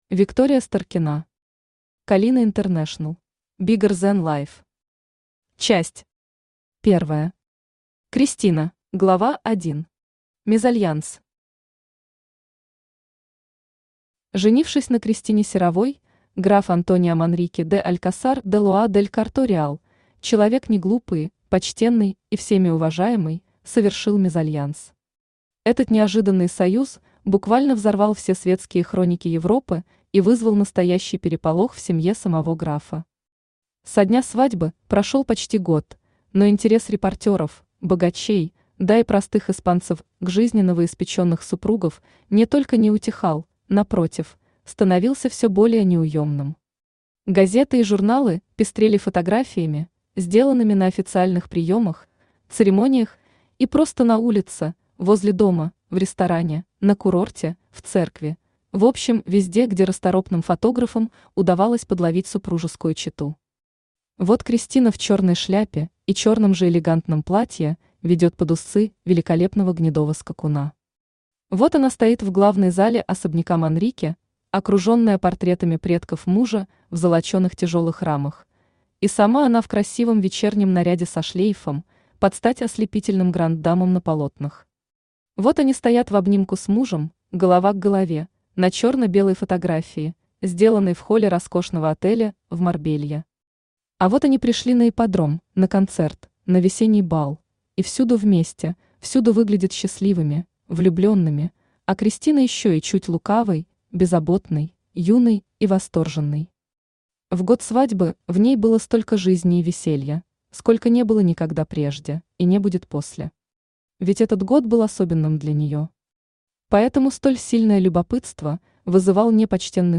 Аудиокнига Калина Интернешнл | Библиотека аудиокниг
Aудиокнига Калина Интернешнл Автор Виктория Старкина Читает аудиокнигу Авточтец ЛитРес.